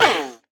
Minecraft Version Minecraft Version 25w18a Latest Release | Latest Snapshot 25w18a / assets / minecraft / sounds / mob / armadillo / hurt4.ogg Compare With Compare With Latest Release | Latest Snapshot
hurt4.ogg